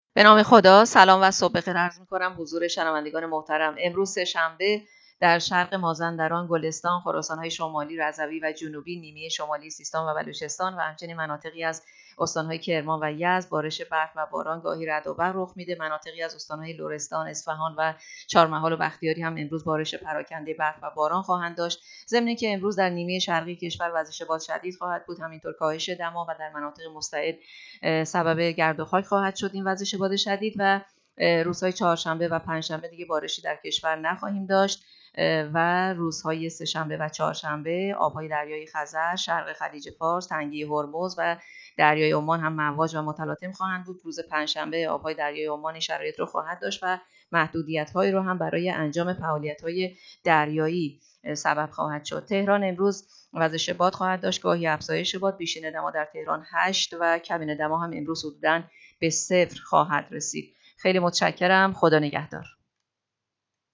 گزارش رادیو اینترنتی پایگاه‌ خبری از آخرین وضعیت آب‌وهوای ۲ بهمن؛